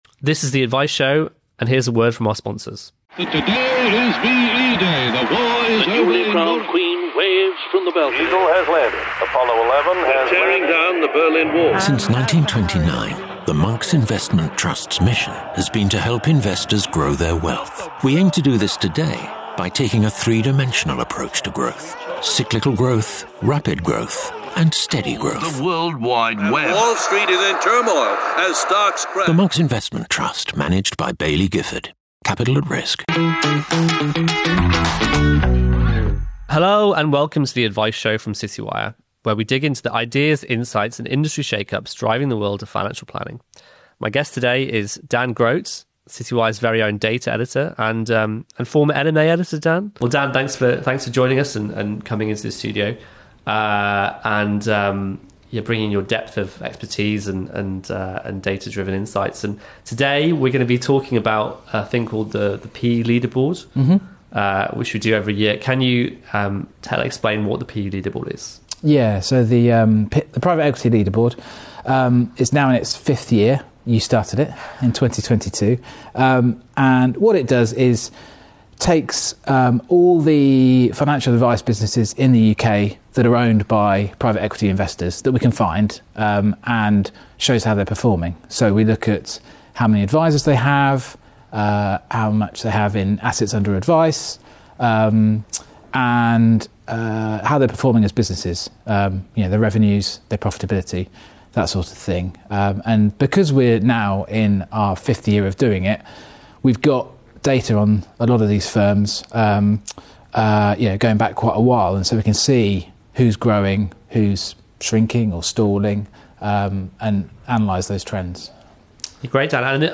Exclusive interviews and lively chat with a diverse mix of figures from the financial planning profession, personal finance and financial services.